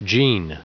La première transcription phonétique est la prononciation britannique et la deuxième la prononciation américaine.
[dZi:nz]